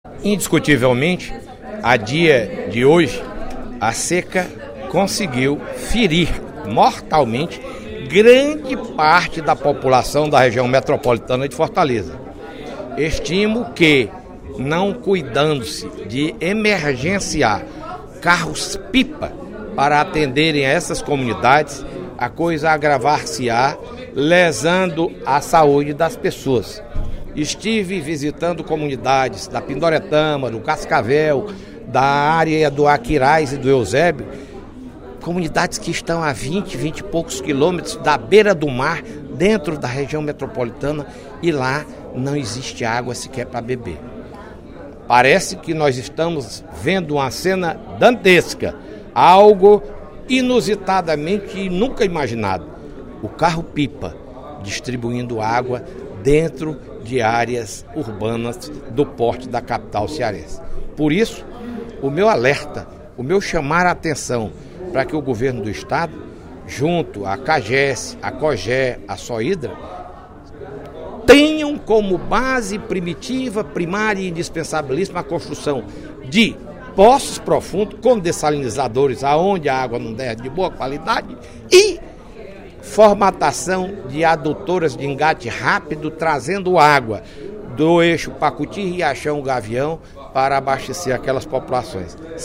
No primeiro expediente da sessão plenária desta quarta-feira (23/10), o deputado Fernando Hugo (SDD) apresentou a sua preocupação com a falta d’água em todo o Estado e, principalmente, na Região Metropolitana de Fortaleza.